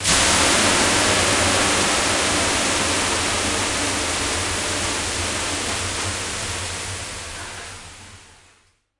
Sonic Pi 3.1 Sauna 发布 新样本 " ambi sauna - 声音 - 淘声网 - 免费音效素材资源|视频游戏配乐下载
在热石头上的桑拿浴室里扔水。在近距离记录Zoom H5。